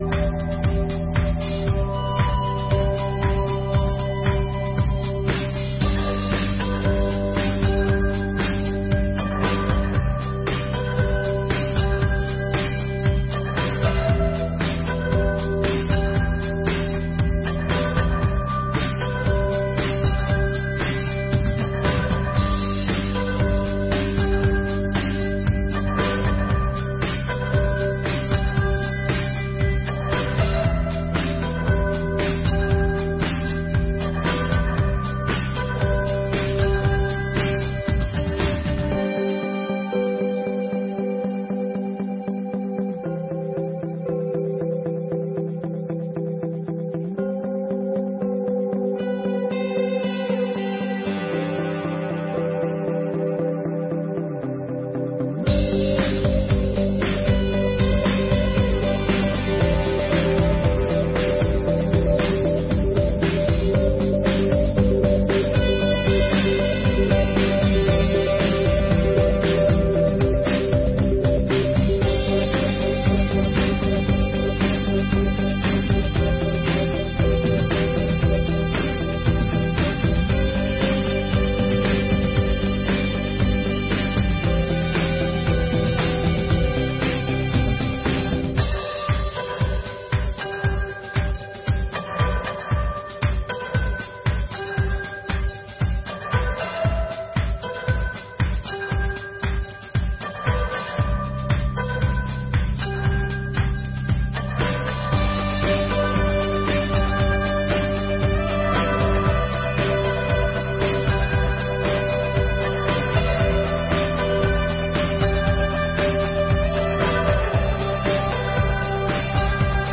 Sessões Solenes de 2023